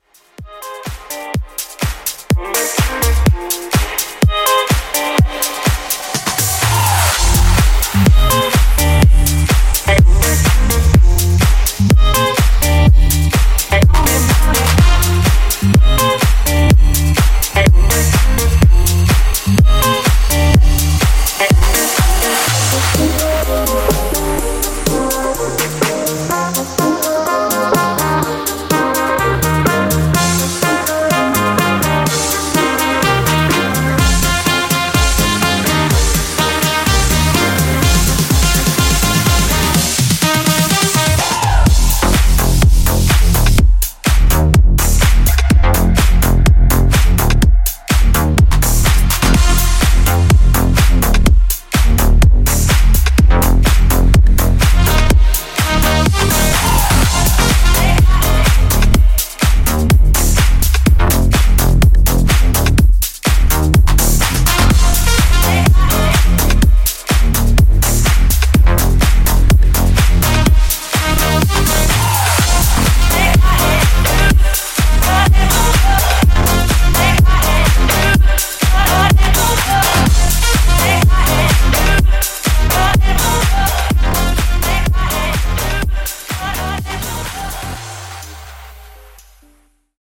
Club Remix Repeat)Date Added